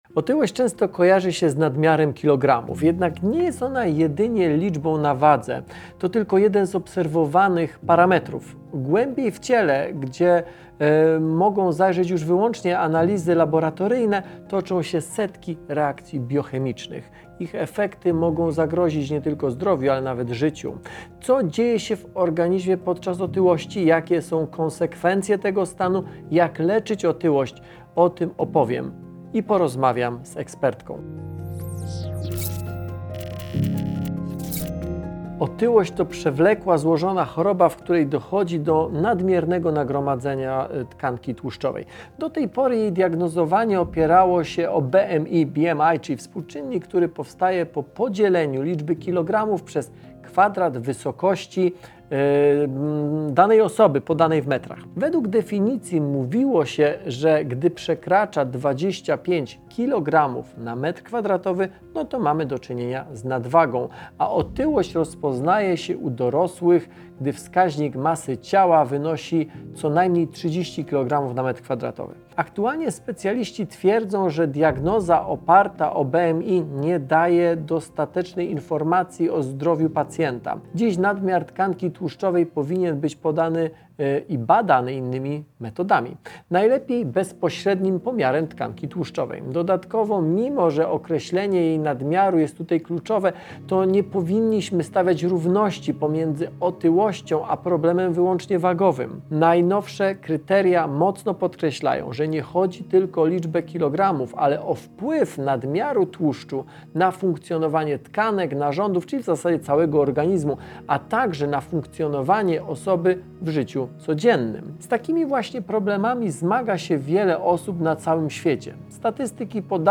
W tym odcinku zabieram Was do zakładu przetwarzania elektrośmieci i krok po kroku pokazuję, jak odzyskujemy cenne surowce.